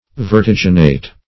Vertiginate \Ver*tig"i*nate\
vertiginate.mp3